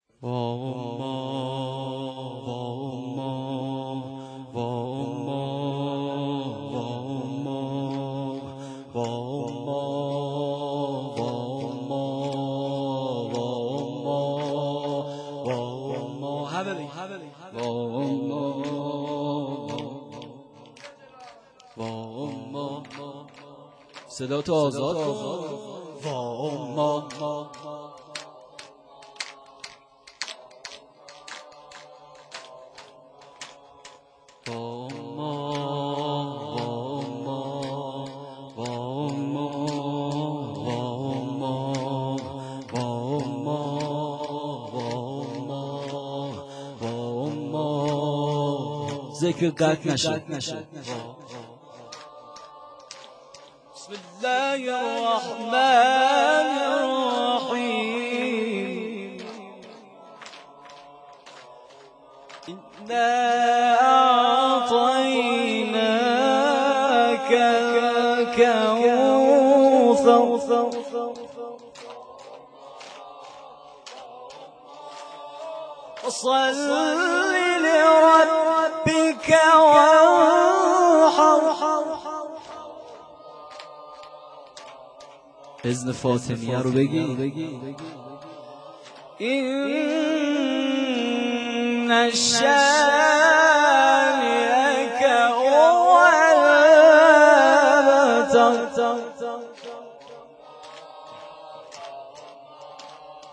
زمینه(سوره کوثر